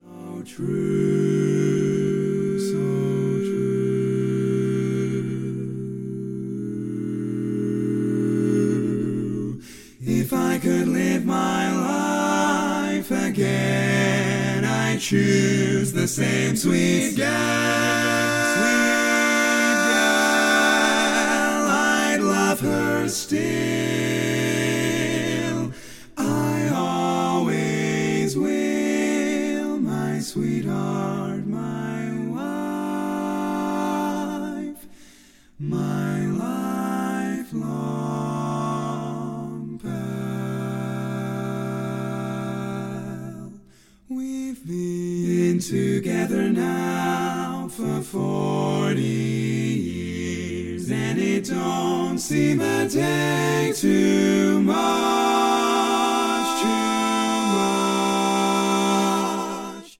Full mix
Category: Male